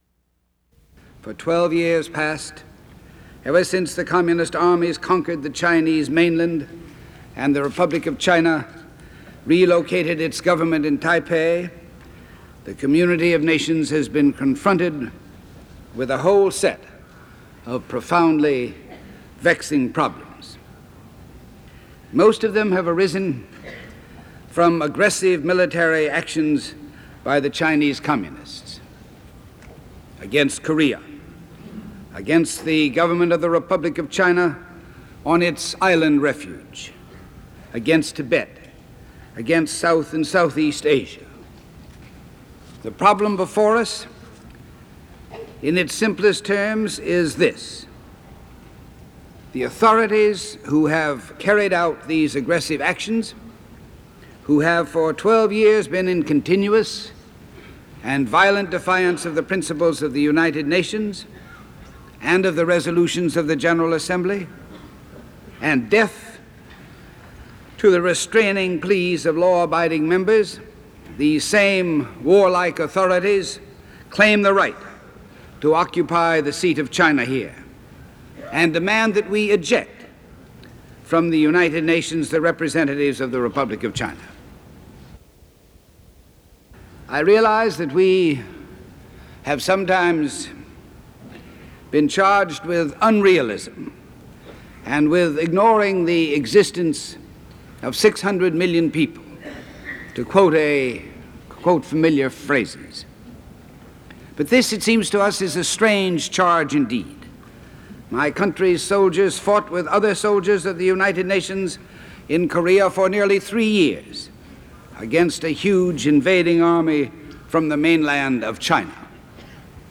U.S. Ambassador to the United Nations Adlai Stevenson speaks against China's membership in the U.N
Broadcasters CBS Radio Network